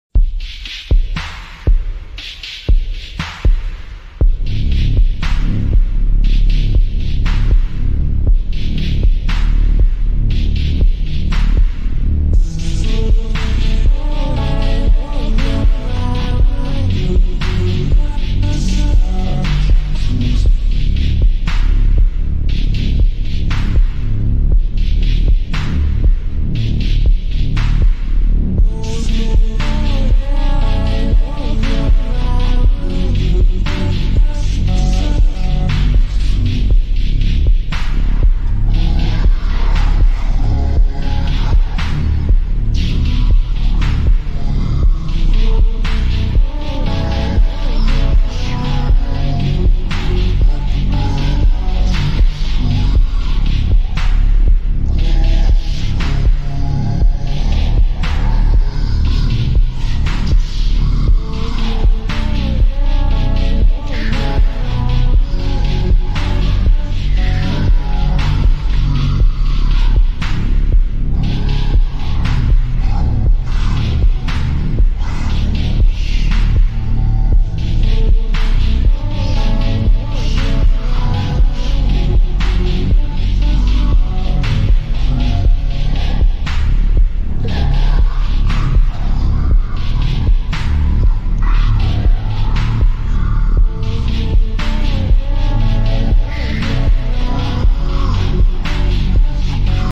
⚠ Warning ⚠ Extreme bassbossted